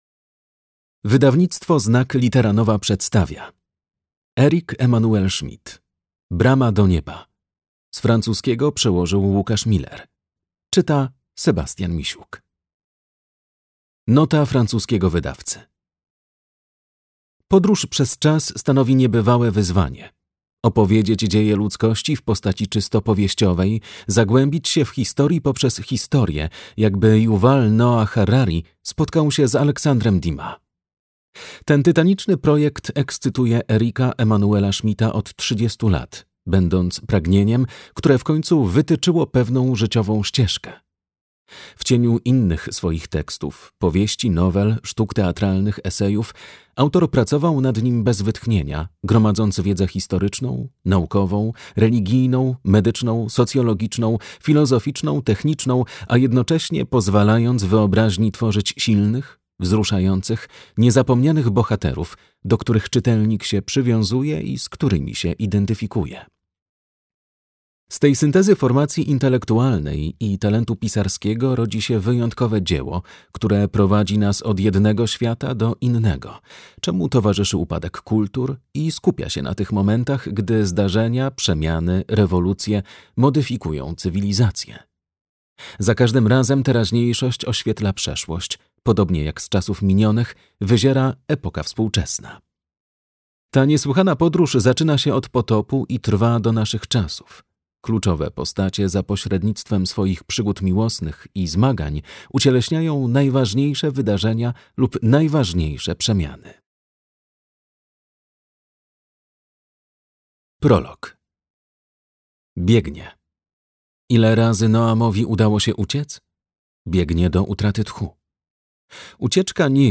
Brama do nieba - Eric-Emmanuel Schmitt - audiobook